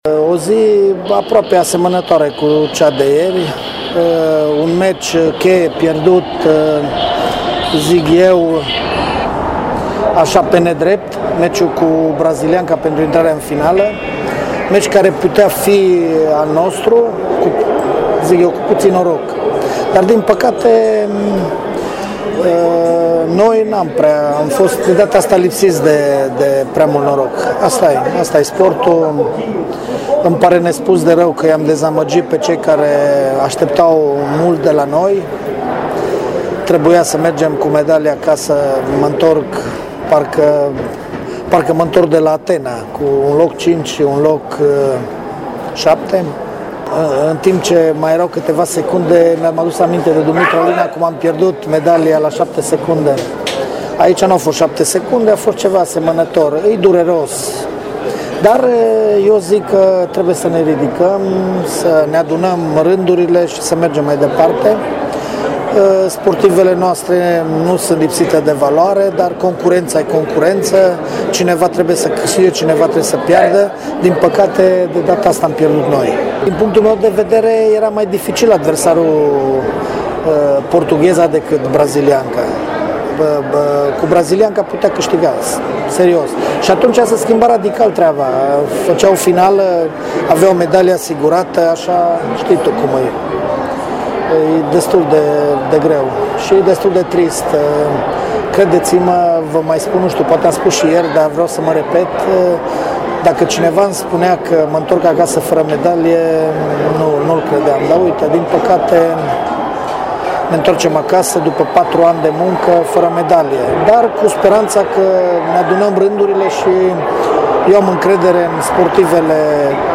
intr-o declarație înregistrată